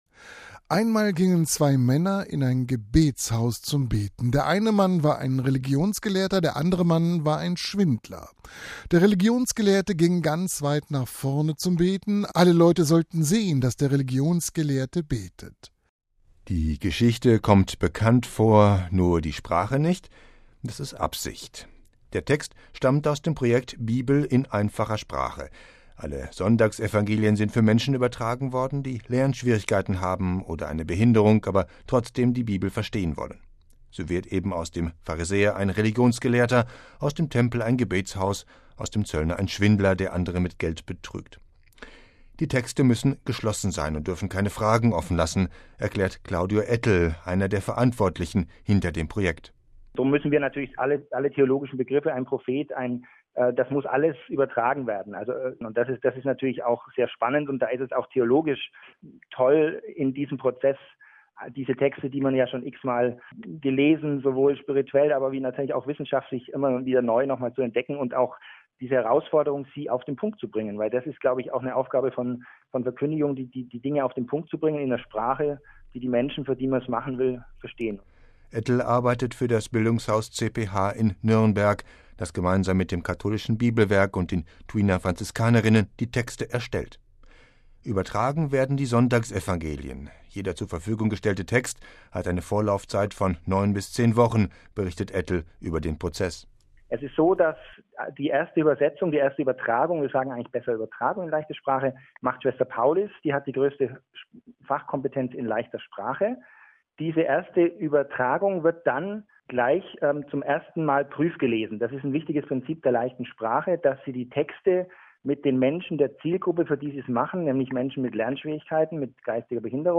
im Gespräch mit Radio Vatikan